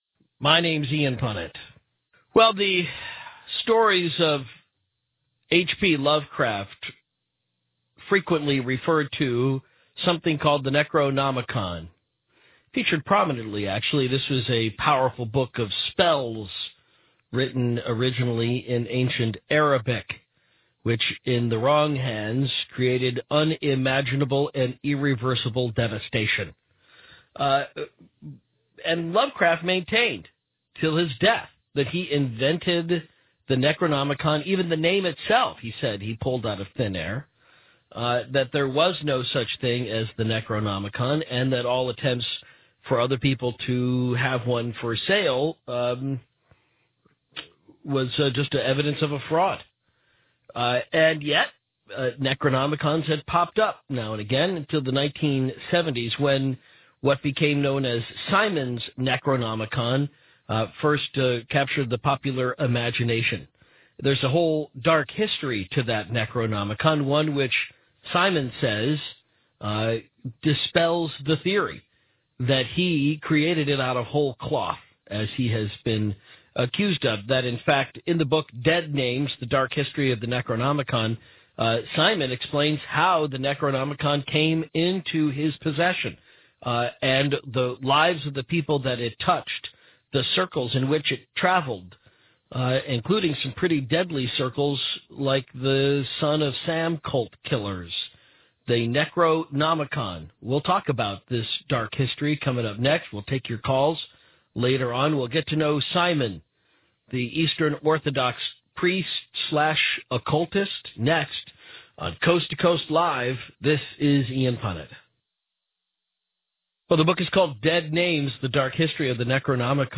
(The original, unaltered audio can be listened to here.)